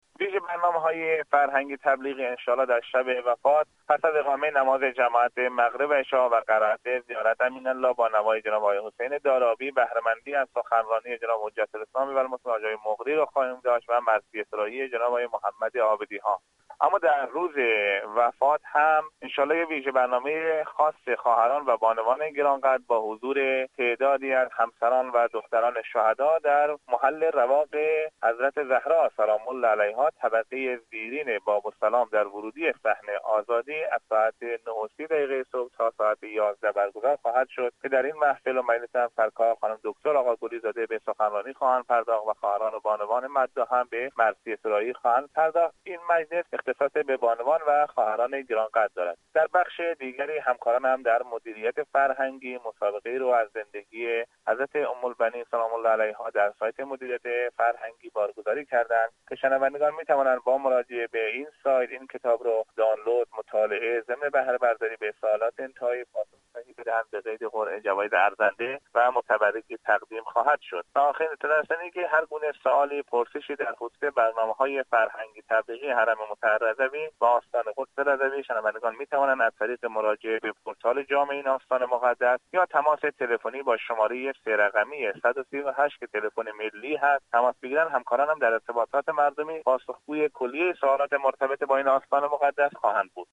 در گفتگو با خبر رادیو زیارت گفت: